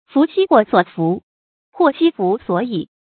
福兮祸所伏，祸兮福所倚 fú xī huò suǒ fú, huò xī fú suǒ yī 成语解释 指福祸互为因果，互相转化。